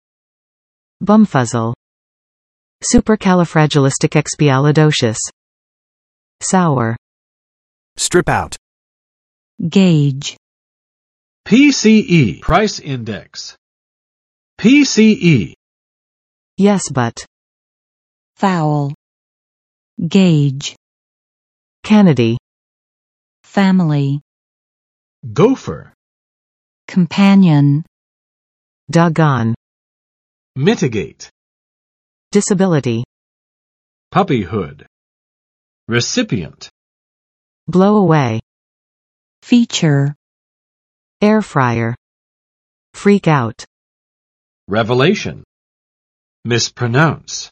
[ˌsupɚkælɪfrædʒəlɪstɪkekspiælɪˈdoʃəs] adj.